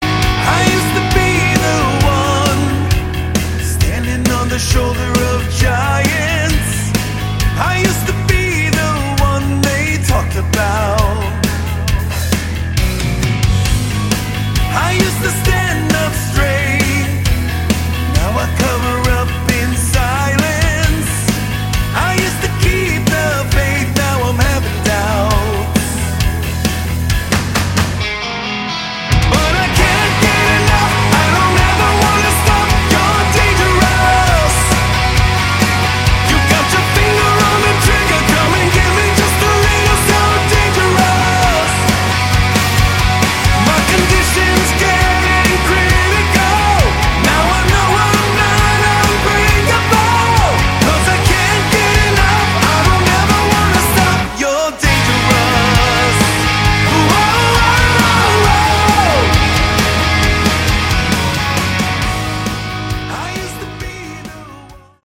Category: Hard Rock
guitar, bass, keyboards, vocals